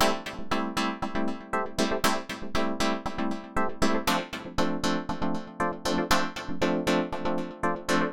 28 Chords PT2.wav